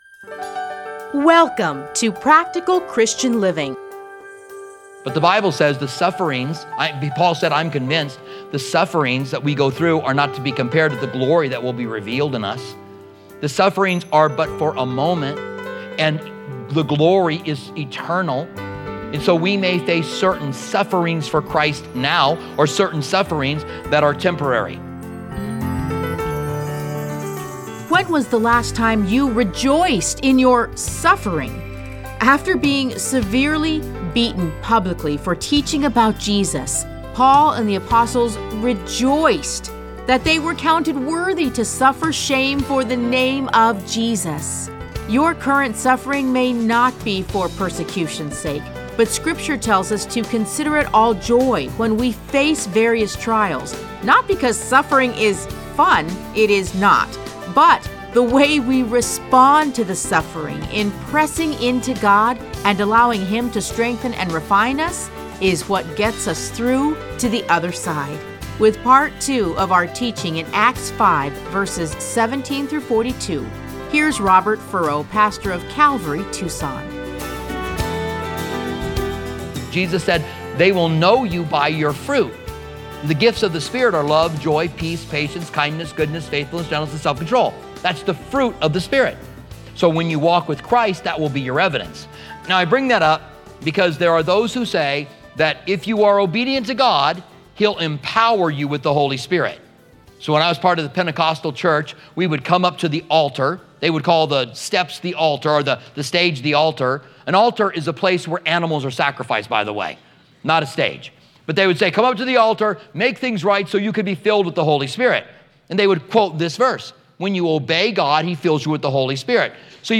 Listen to a teaching from Acts 5:17-42.